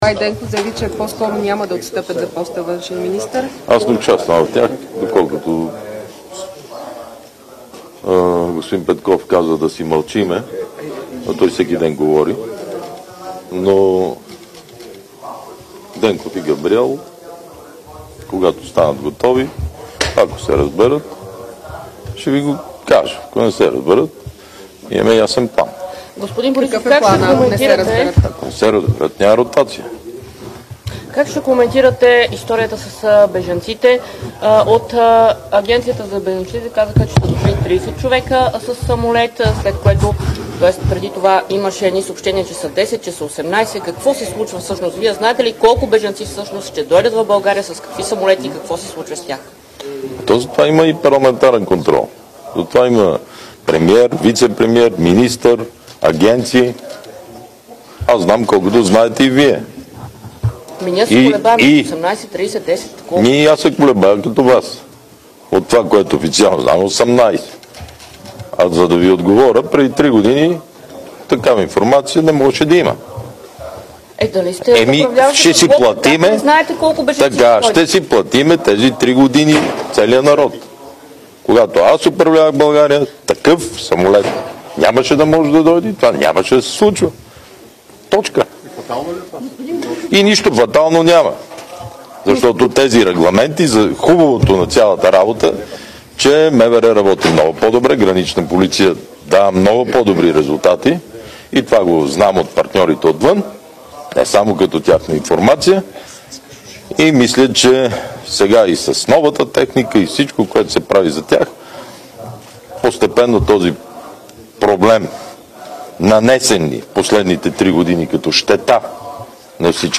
. - директно от мястото на събитието (Народното събрание)
Директно от мястото на събитието